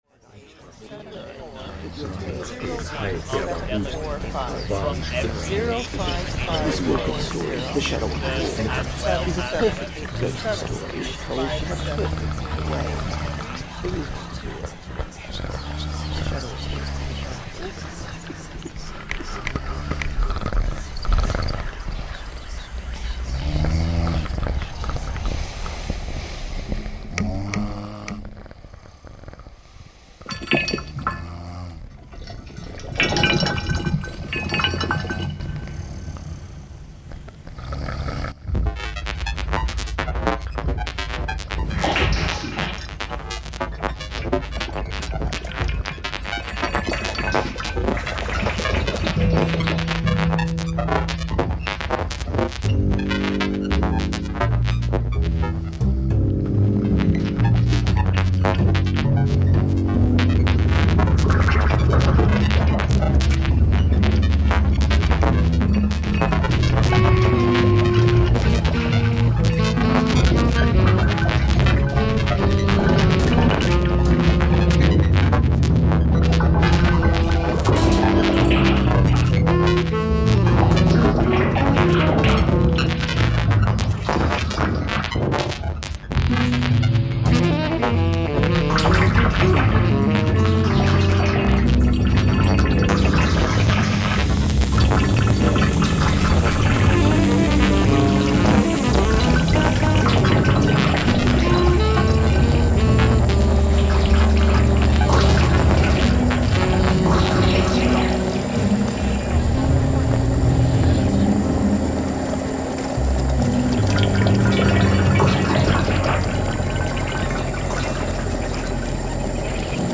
Saxophone, foot pedal bass, electronics.